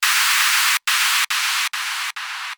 Hier noch etwas zur Aufhellung. Rosa Rauschen von 0° bis 60° Diese Dateien erstellen wir bei unseren Chassis-Testen um einen ersten Eindruck zu bekommen wie der Klang sich unter Winkel verändert.